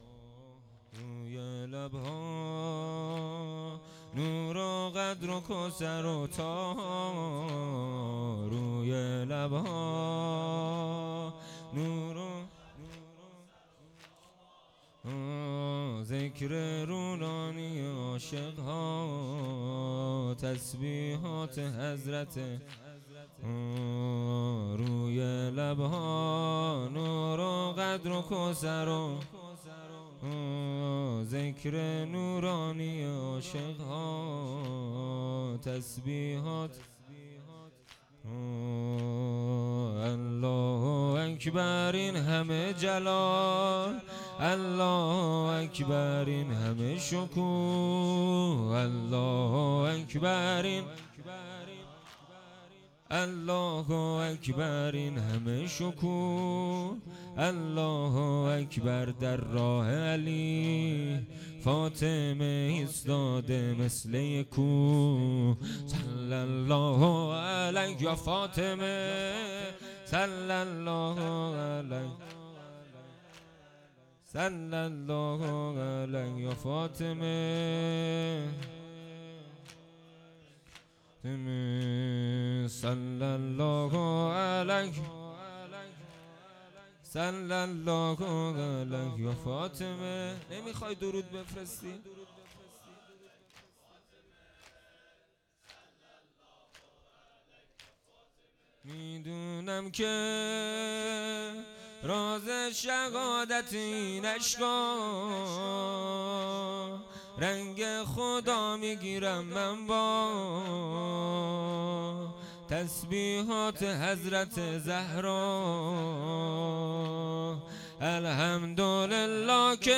فاطمیه دهه اول 1402